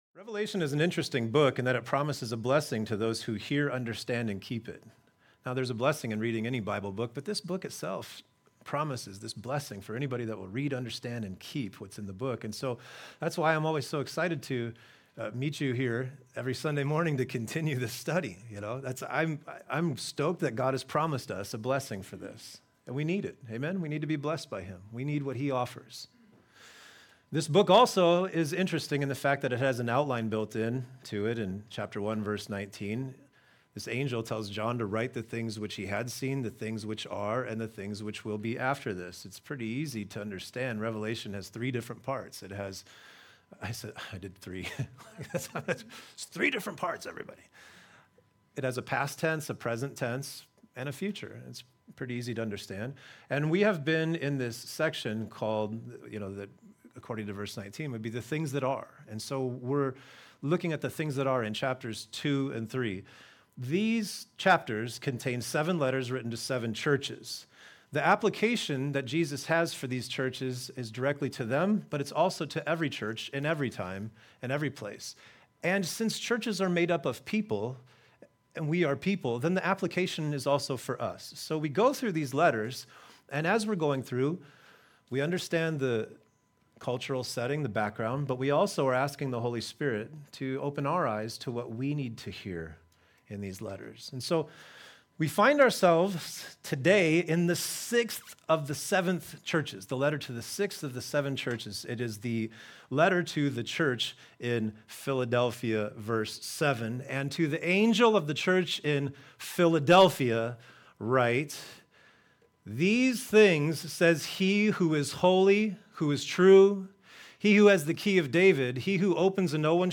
A message from the series "Revelation."